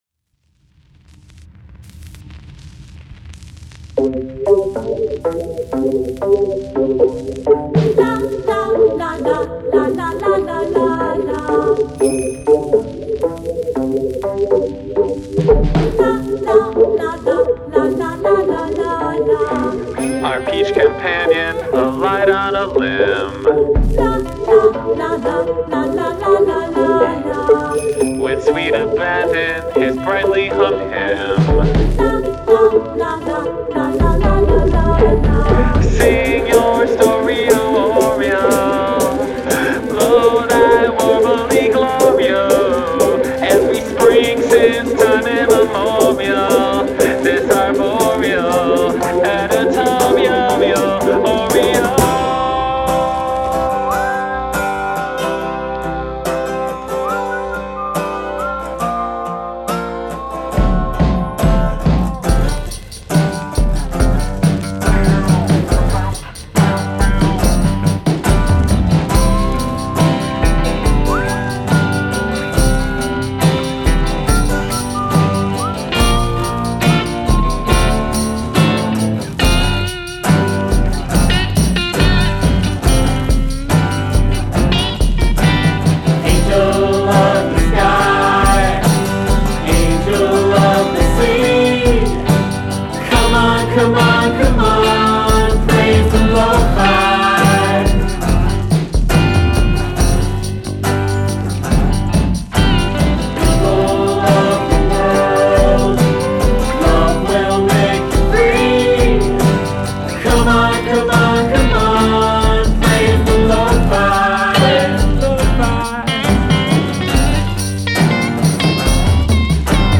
additional vocals
trumpet